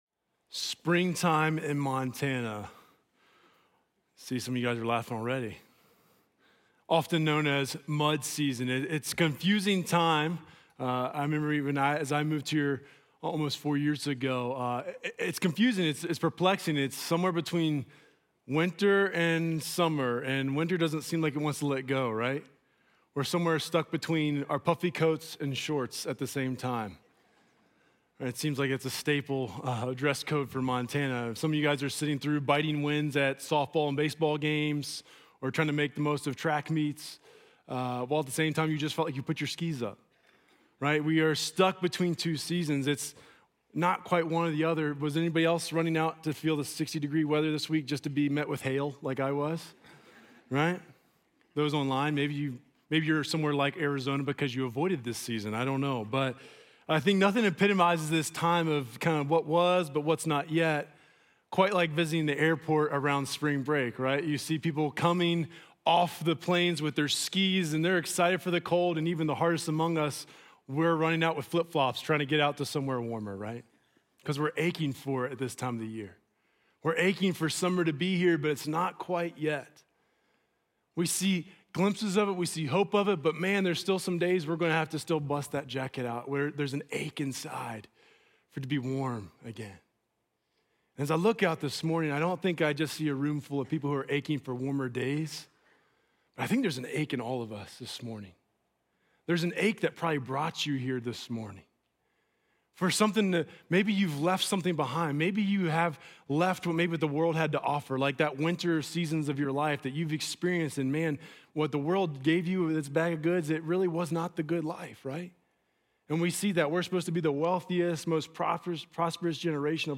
Journey Church Bozeman Sermons Aches…